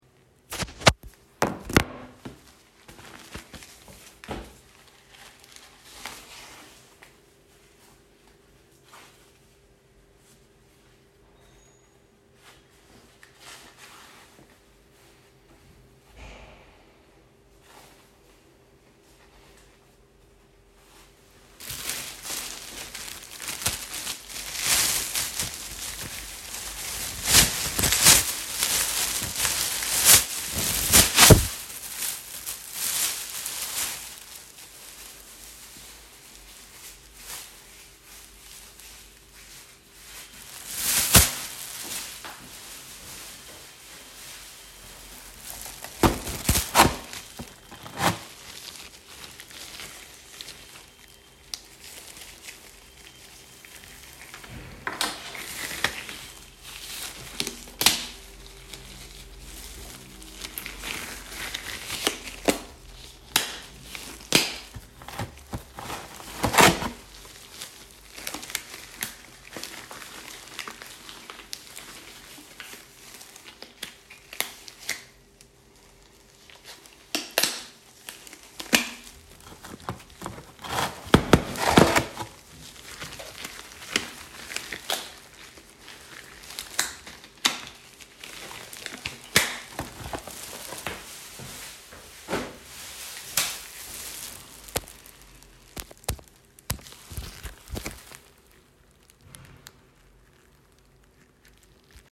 The sound of an NHS health worker preparing protective equipment
Recording of a health service worker in the UK donning protective equipment